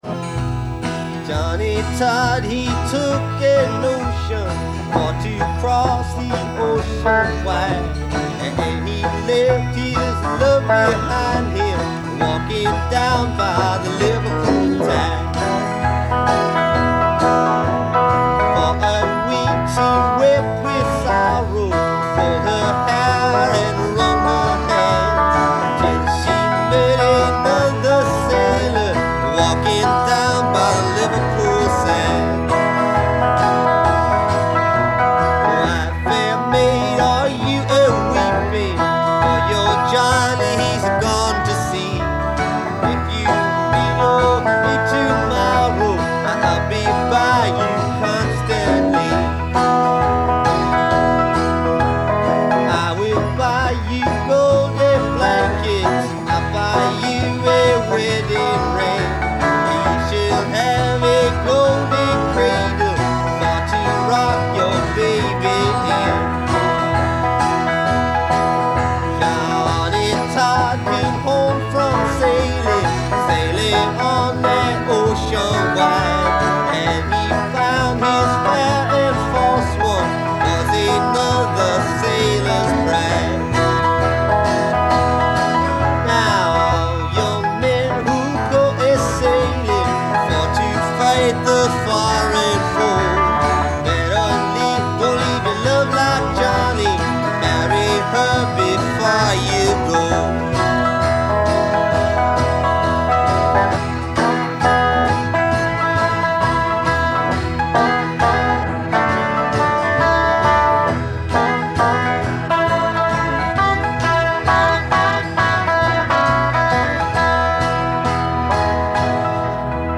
OK, it’s a traditional sea shanty performed by Bob Dylan and The Band during their Basement Tapes recording sessions.